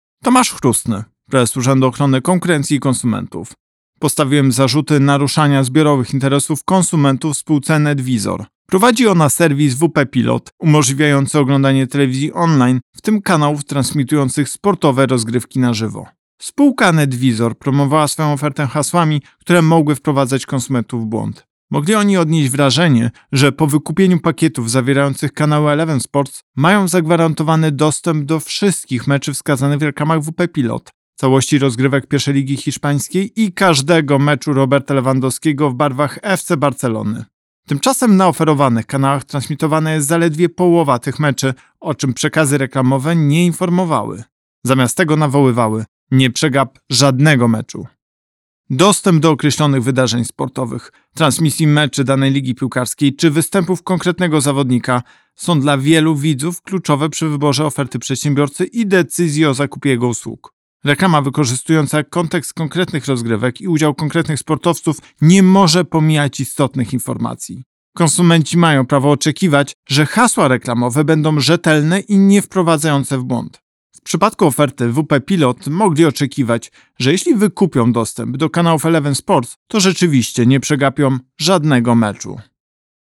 Po wystąpieniu miękkim do spółki Netwizor i przeprowadzeniu postępowania wyjaśniającego, Prezes UOKiK Tomasz Chróstny postawił przedsiębiorcy zarzuty naruszania zbiorowych interesów konsumentów. Pobierz wypowiedź Prezesa UOKiK Tomasza Chróstnego Spółka Netwizor promowała swoją ofertę hasłami, które mogły wprowadzać konsumentów w błąd.